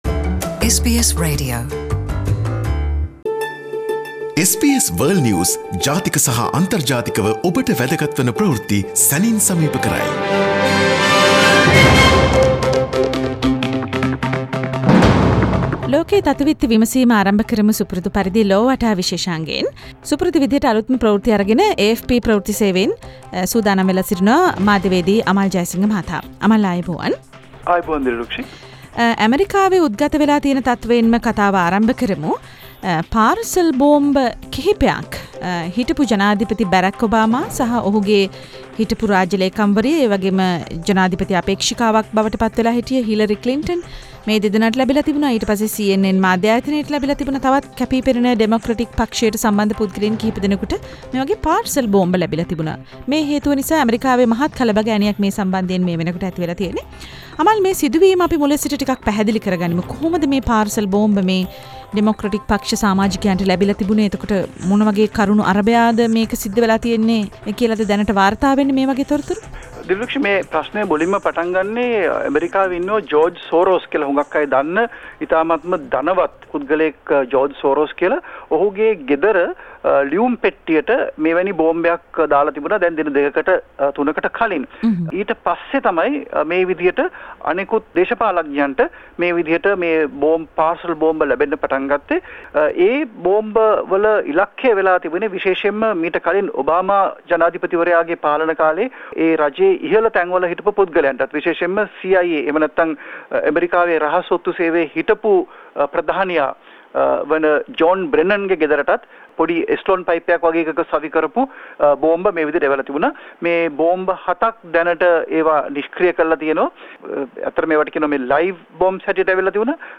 විදෙස් විත්ති සමාලෝචනය
SBS සිංහල ගුවන් විදුලියේ සිකුරාදා වැඩසටහනින්